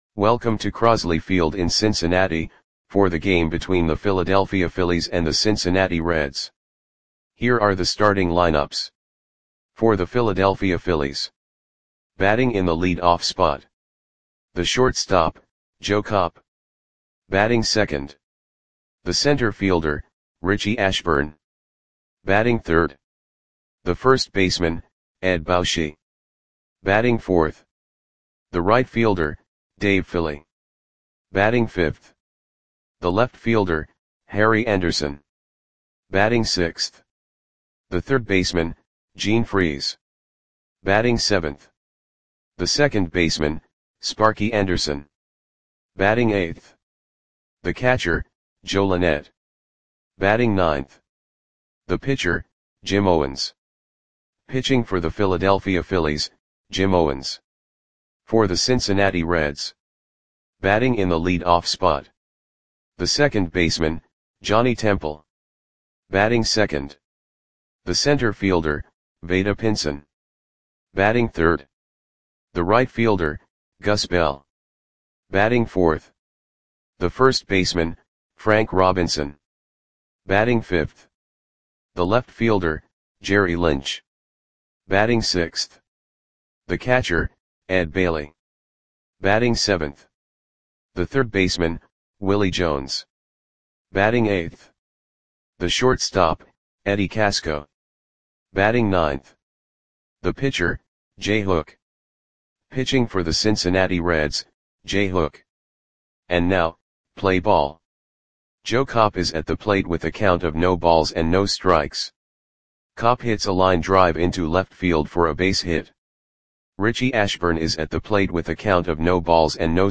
Audio Play-by-Play for Cincinnati Reds on July 24, 1959
Click the button below to listen to the audio play-by-play.